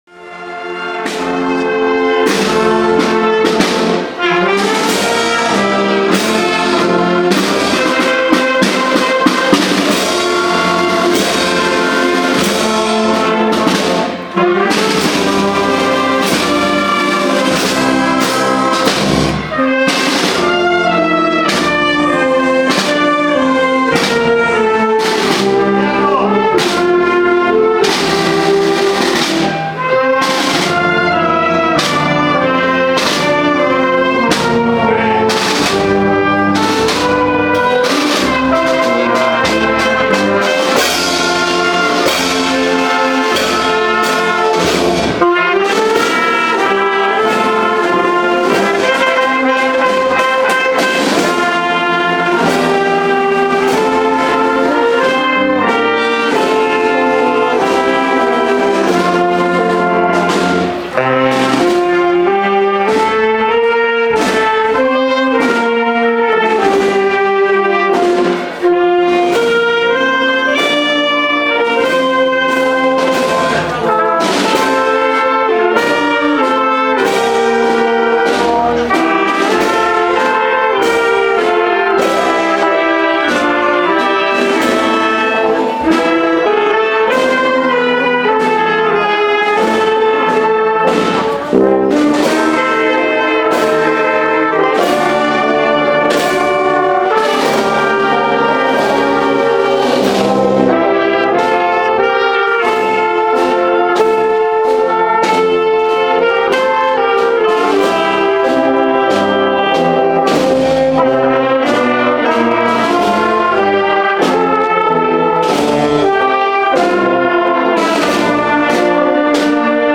Procesión del Corpus Christi - Totana 2014
Tomaron parte en ella numerosos vecinos que acompañaron al Santísimo, que procesionó en el esplendoroso trono. También salieron en ella un buen número de niños y niñas ataviados con sus trajes de Primera Comunión, autoridades municipales, así como la banda de la Agrupación Musical de Totana, que cerró la comitiva interpretando marchas clásicas de esta festividad como 'Triunfal'.